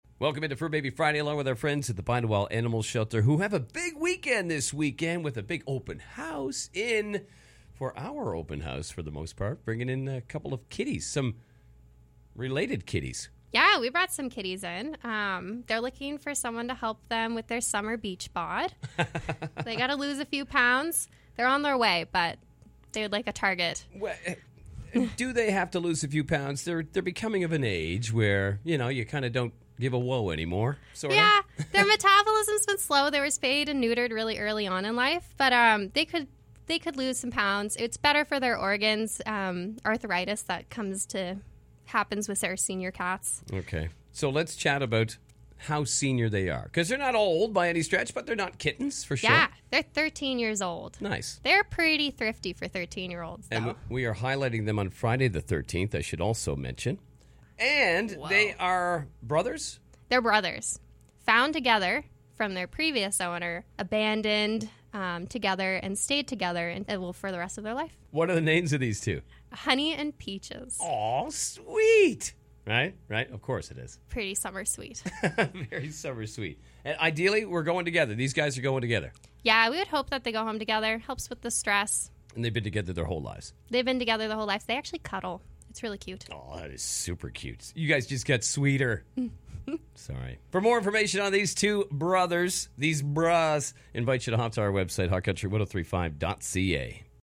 Have a listen to our chat with the Bide Awhile crew…